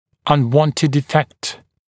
[ʌn’wɔntɪd ɪ’fekt][ан’уонтид и’фэкт]нежелательный эффект